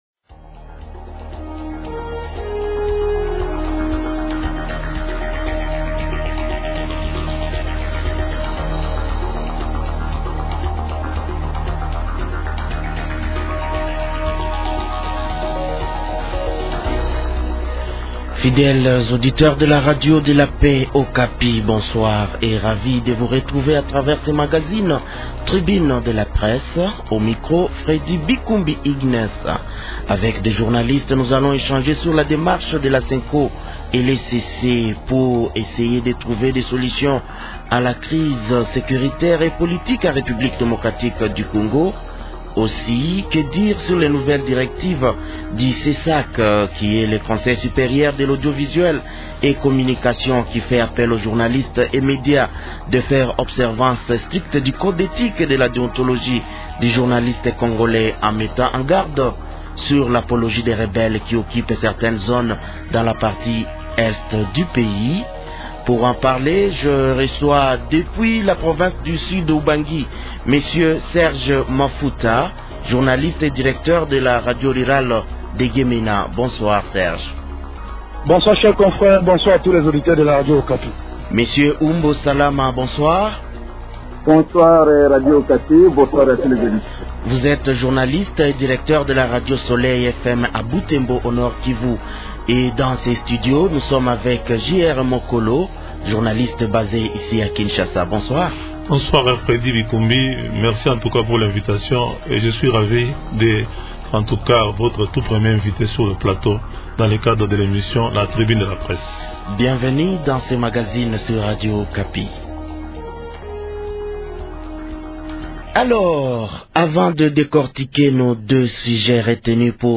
journaliste indépendant basé à Kinshasa